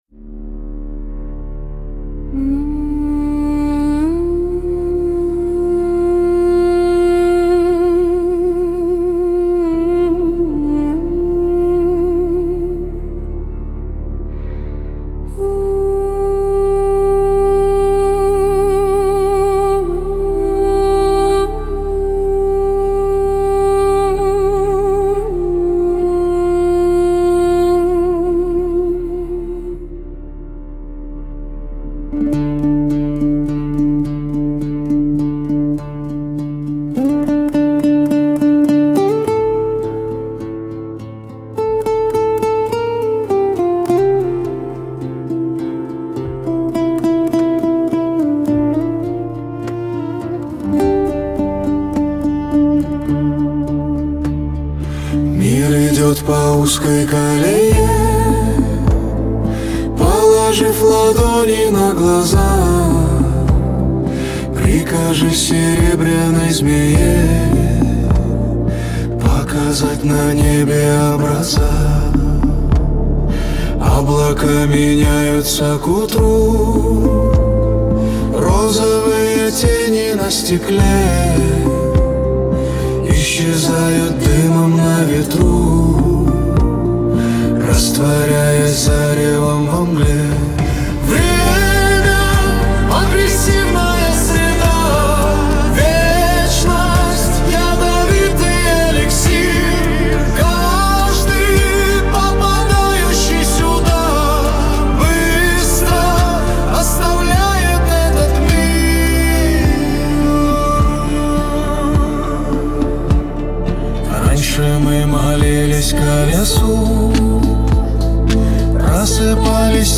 mp3,9208k] Фолк